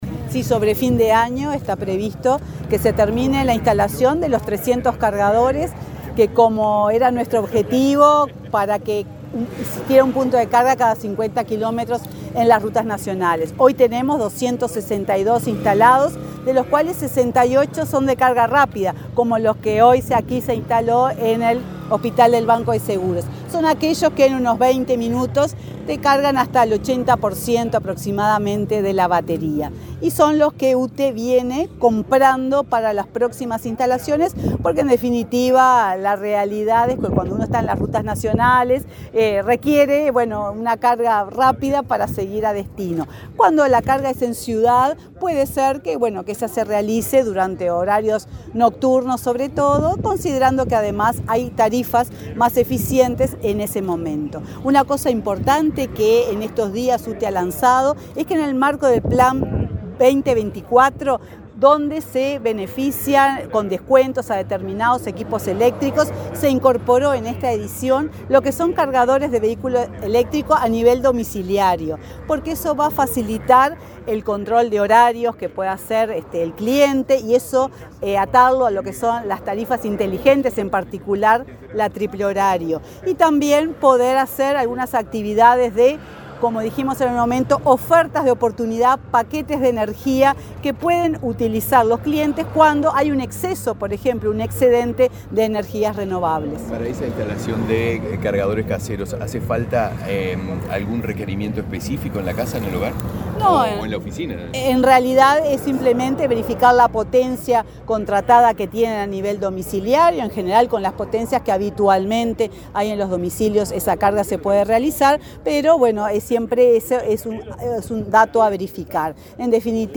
Declaraciones de la presidenta de UTE, Silvia Emaldi
Luego, la titular del ente dedicado a la energía dialogó con la prensa sobre la temática.